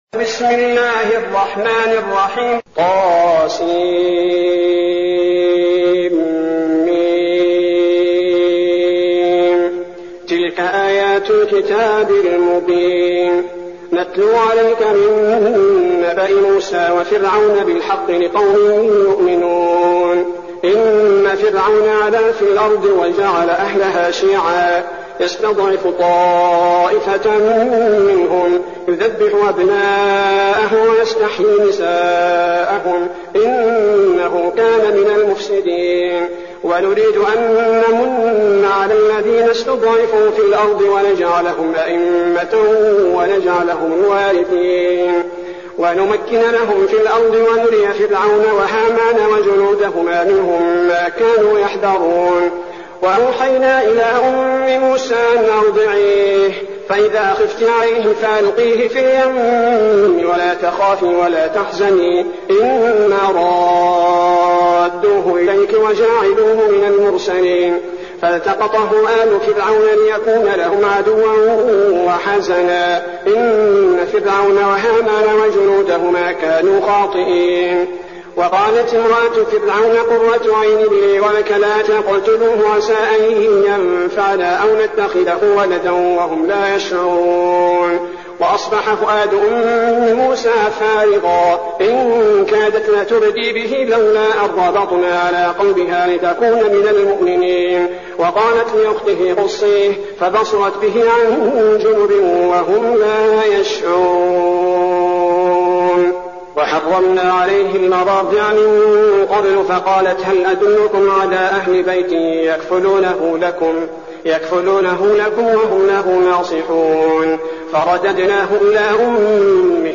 المكان: المسجد النبوي الشيخ: فضيلة الشيخ عبدالباري الثبيتي فضيلة الشيخ عبدالباري الثبيتي القصص